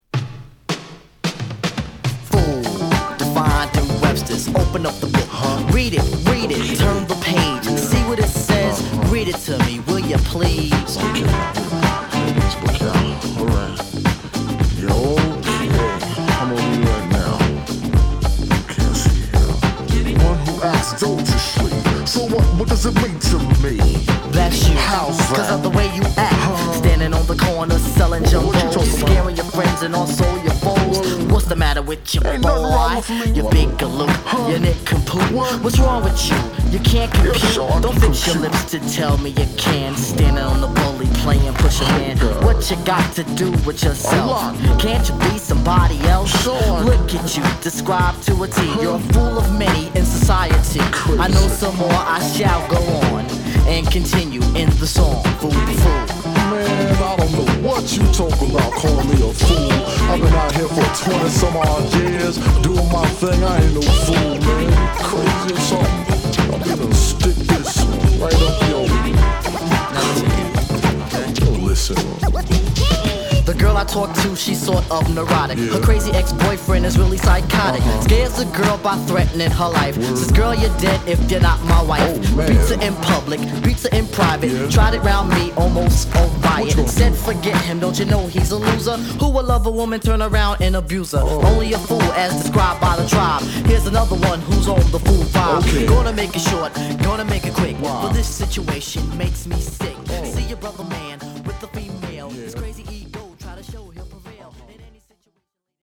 オールド・スクール〜ディスコとしても素晴らしい！